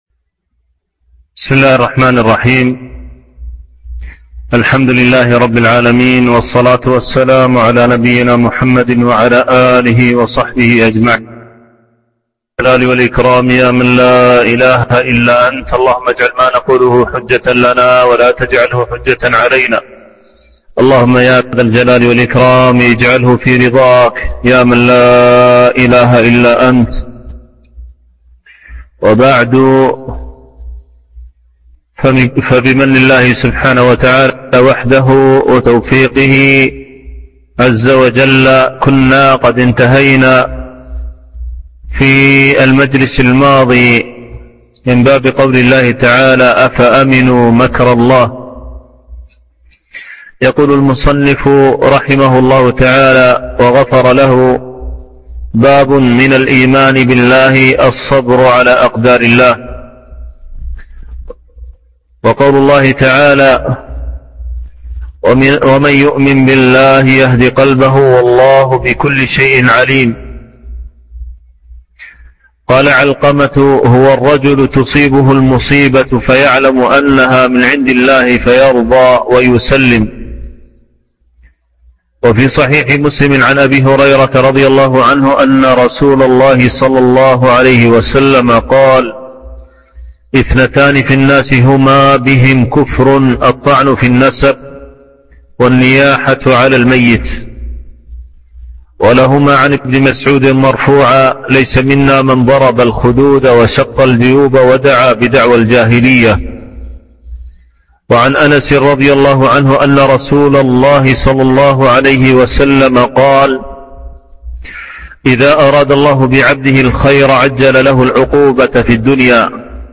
شرح كتاب التوحيد - الدرس الواحد والثلاثون